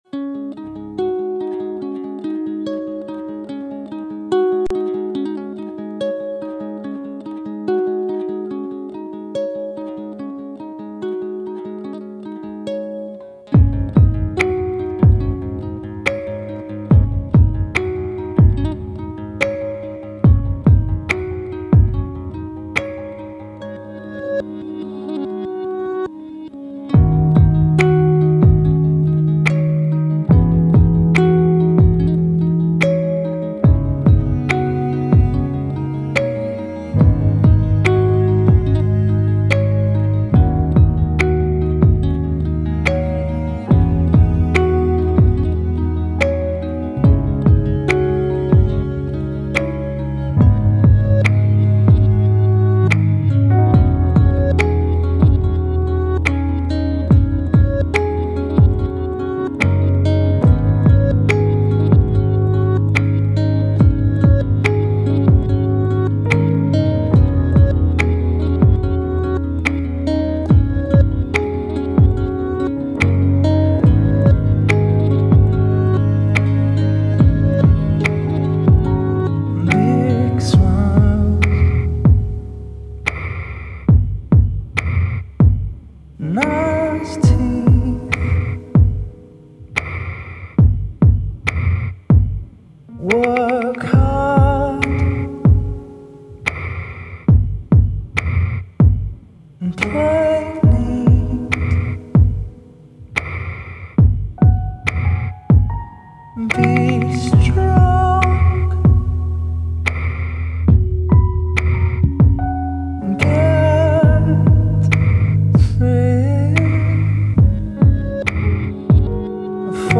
Talk stylings bring a soulful moody track from London boys.